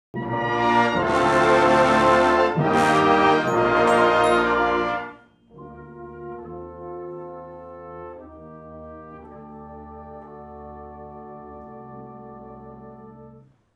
(Full Brass)
arranged in this version for full Brass Band